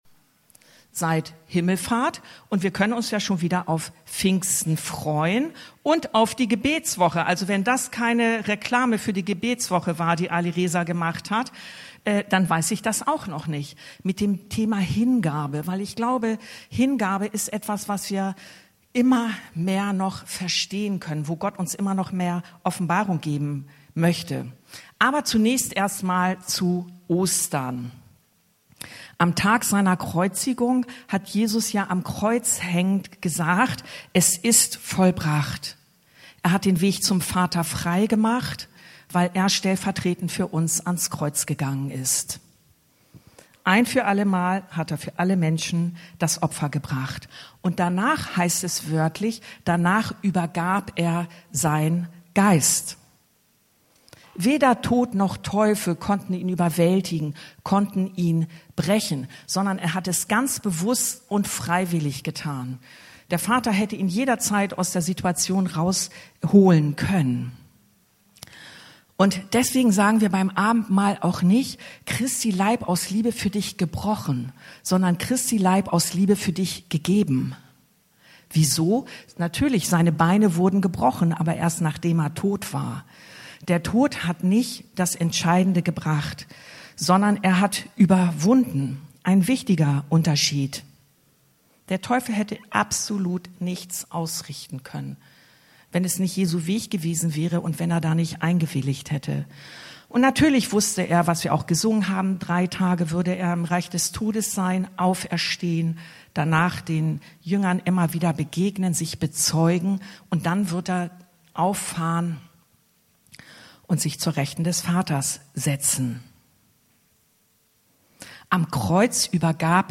Predigten Podcast